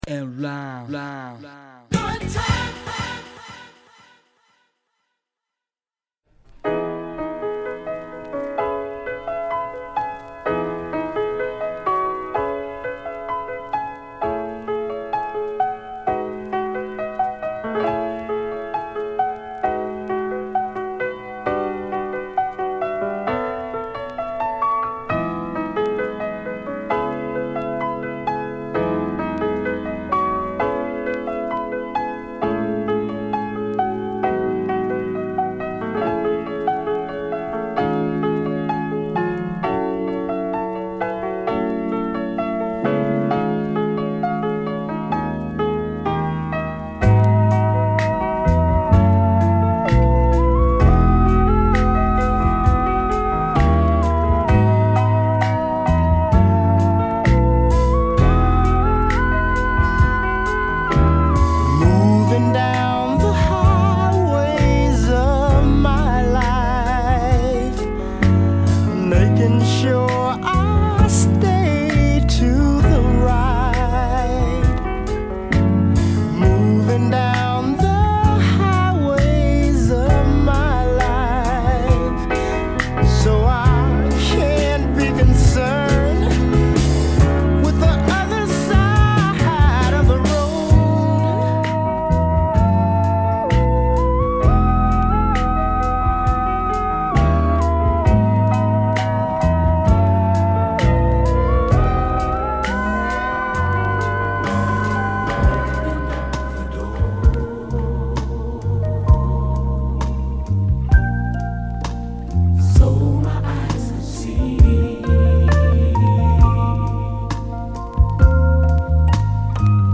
甘いライトメロウなAOR〜FusionやSOULの定番ネタまで
ハートウォーミングでノスタルジックな空気感をミディアムテンポのgrooveでキープ。
Mellow Groove , Mix CD